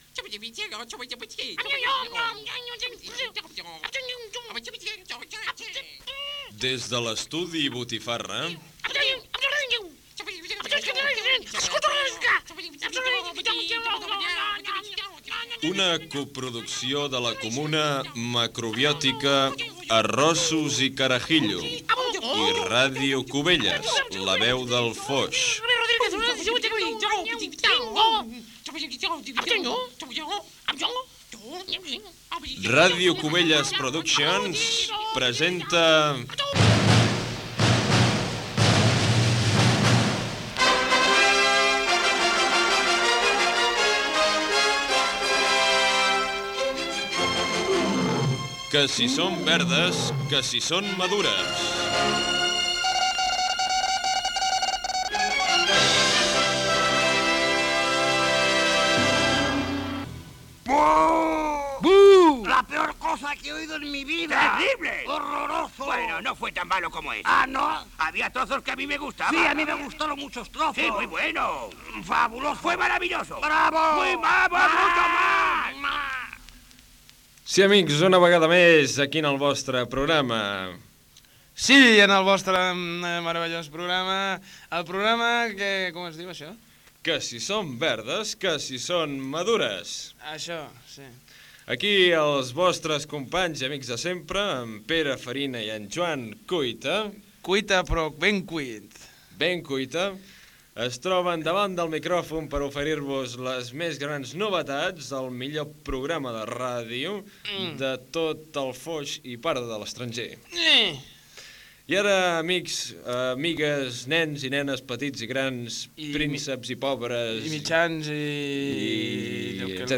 Careta amb la presentació del programa amb un fragment de "The Muppets". Presentació de la secció "Els informalius" i fragment amb algunes notícies inventades de caràcter humorístic.
Entreteniment